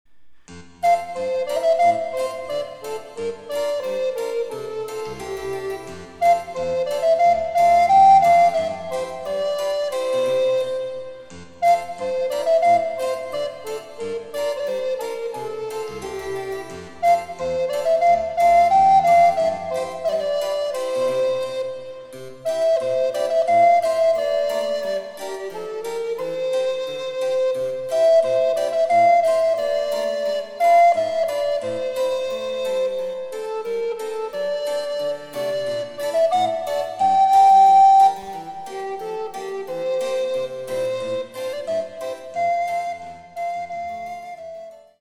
アルトリコーダー独奏（チェンバロ伴奏）
デジタルサンプリング音源使用